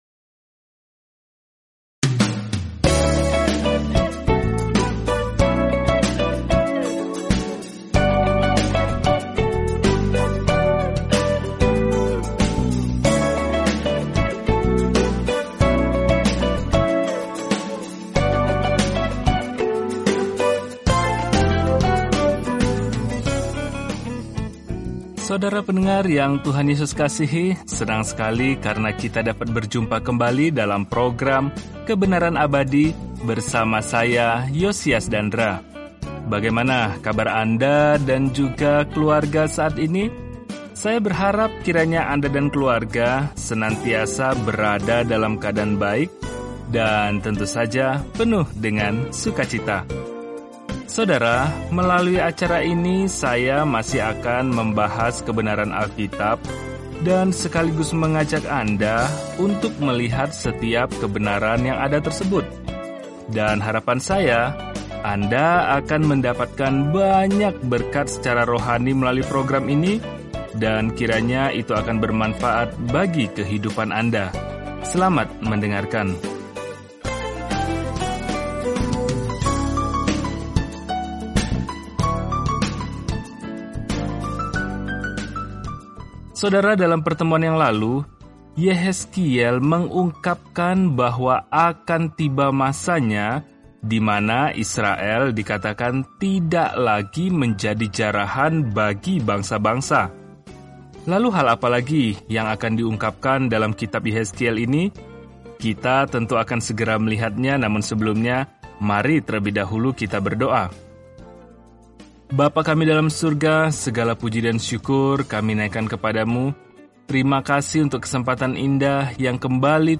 Firman Tuhan, Alkitab Yehezkiel 35 Yehezkiel 36 Yehezkiel 37:1-4 Hari 20 Mulai Rencana ini Hari 22 Tentang Rencana ini Orang-orang tidak mau mendengarkan peringatan Yehezkiel untuk kembali kepada Tuhan, jadi dia malah memerankan perumpamaan apokaliptik, dan itu menusuk hati orang-orang. Jelajahi Yehezkiel setiap hari sambil mendengarkan pelajaran audio dan membaca ayat-ayat tertentu dari firman Tuhan.